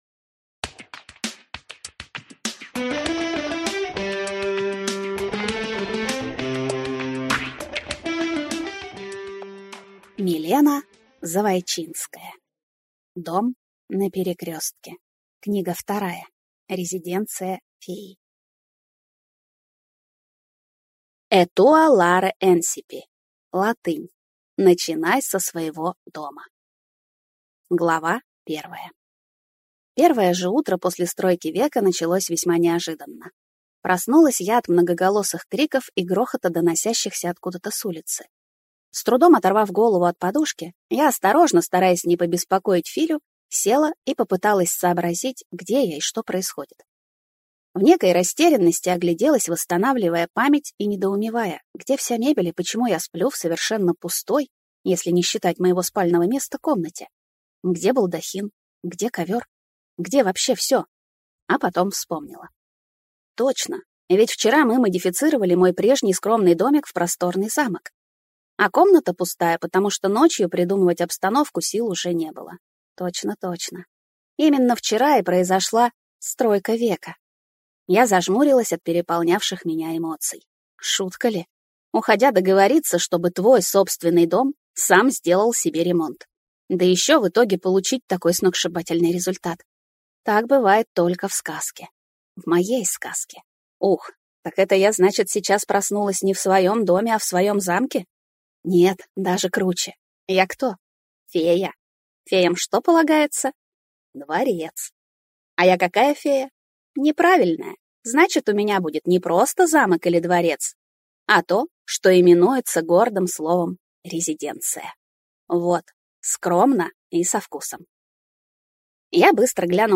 Аудиокнига Дом на перекрестке. Резиденция феи | Библиотека аудиокниг